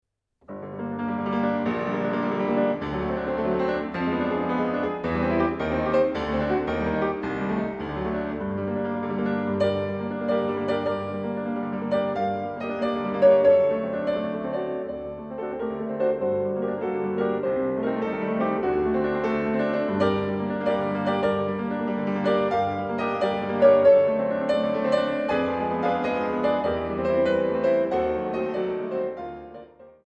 fis-moll Allegro leggiero